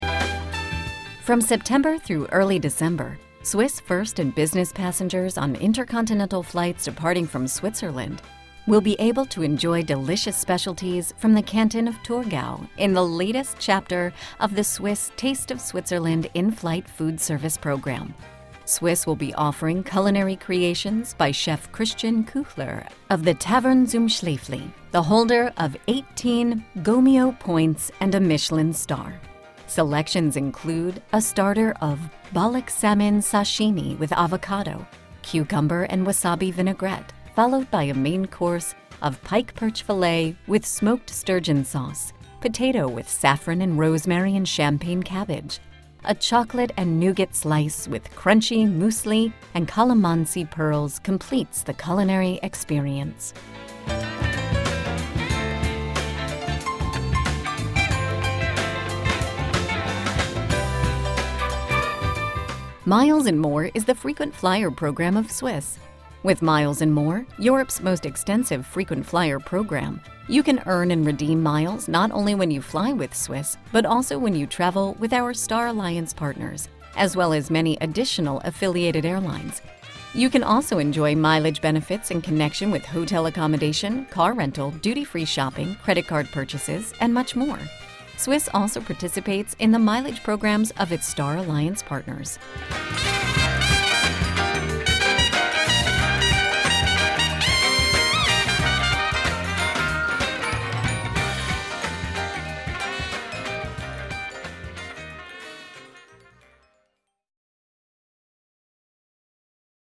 Here are samples of actual Informer Messages on hold.